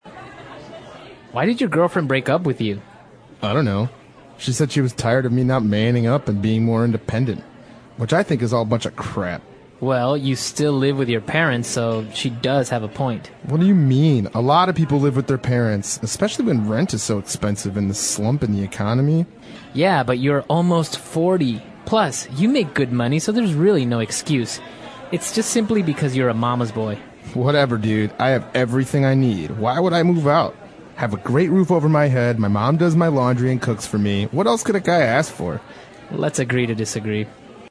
外教讲解纯正地道美语|第347期:Living With Your Parents 和父母住在一起